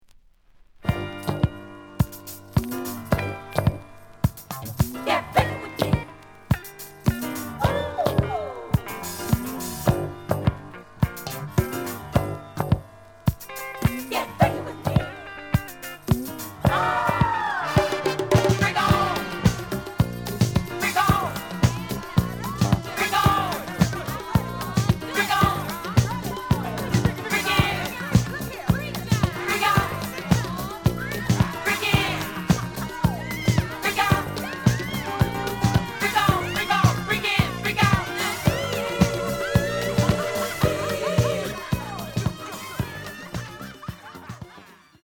The audio sample is recorded from the actual item.
●Genre: Disco
Slight affect sound.